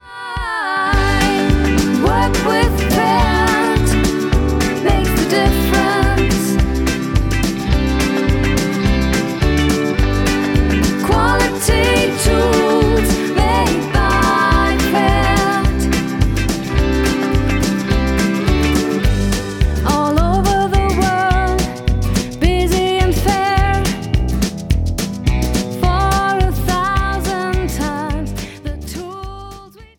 • Markensong